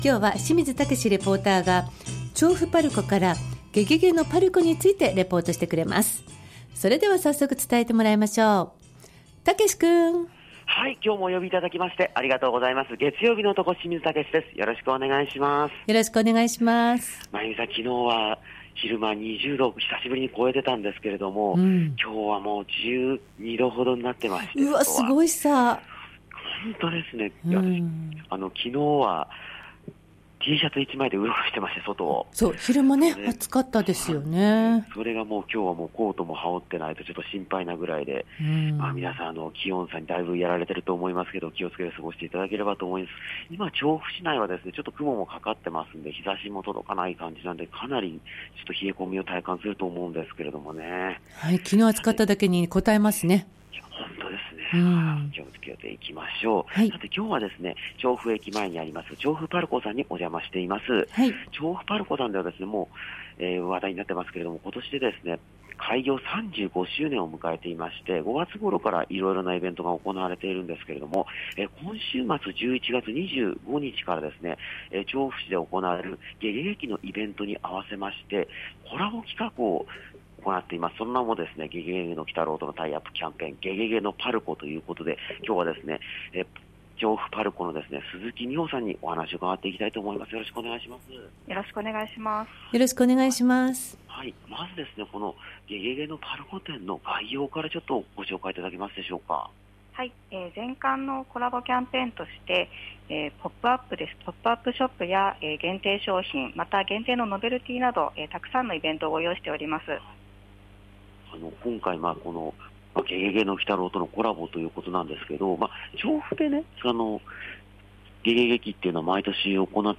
冬の気候となった空の下からお届けした本日の街角レポートは、今年で３５周年、調布PARCOさんからお届けしました！